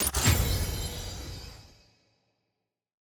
sfx-perks-prec-keystone-pta.ogg